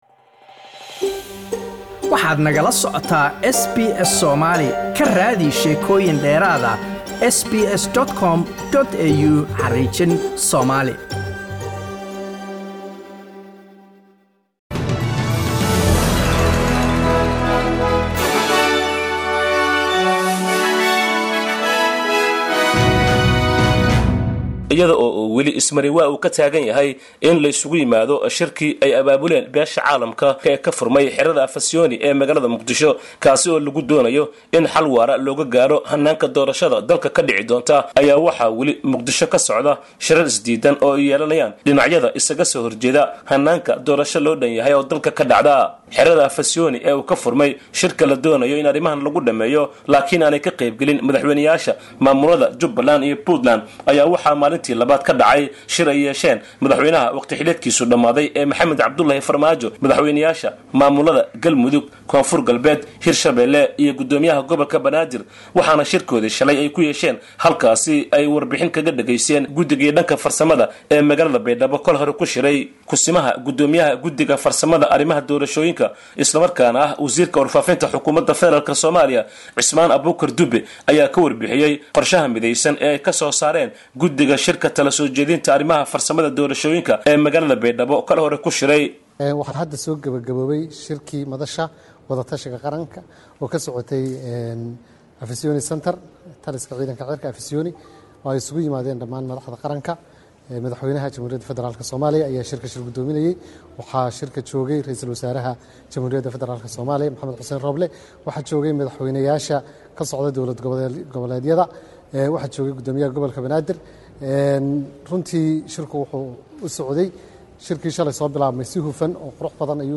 Khilaafkii siyaasiyiinta Soomaaliya ayaa illaa hadda la xallin la'yahay. Garabka xukuumadda iyo siyaasiyiinta kale ee kasoo hor jeeda ayaan weli kulmin iyadoo ay u kala socdaan shirar kala gooni ahi. Qaar ka mid ah shacabka Muqdisha ayaa iyaguna ra'yigooda ka dhiibanayaa arrintaas.